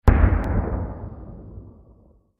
دانلود آهنگ نبرد 7 از افکت صوتی انسان و موجودات زنده
جلوه های صوتی